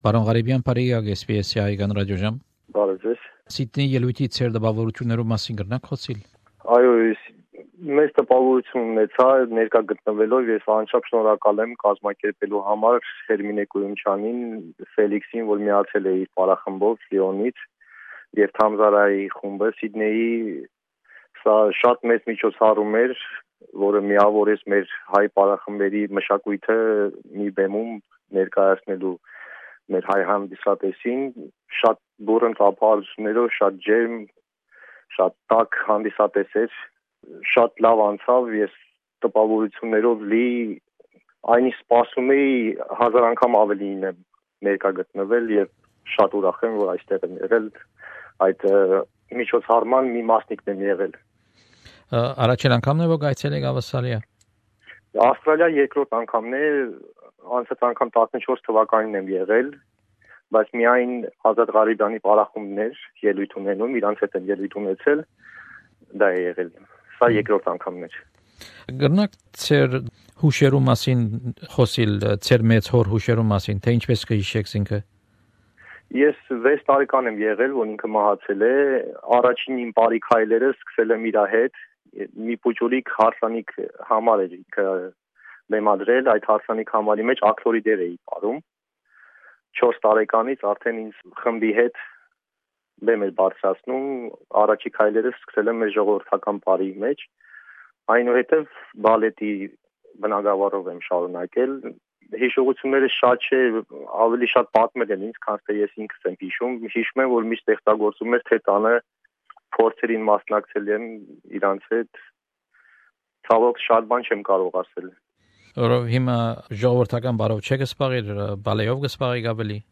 Հարցազրոլյց